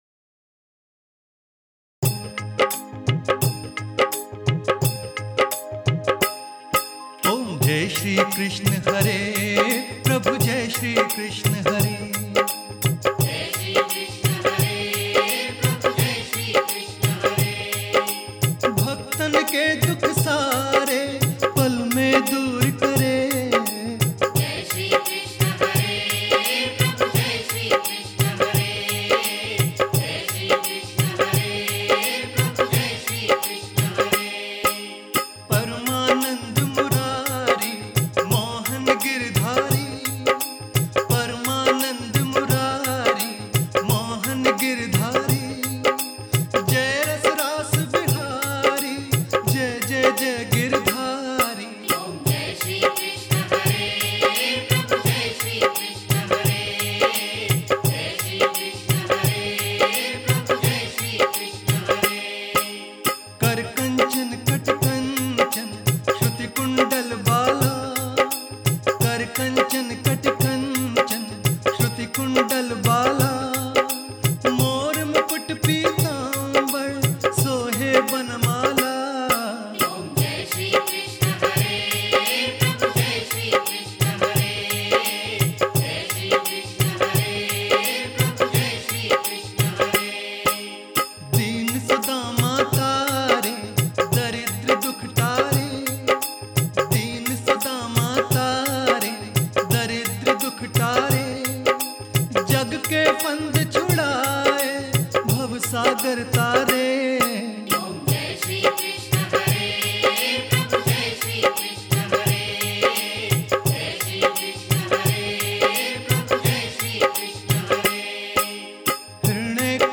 Aartiyan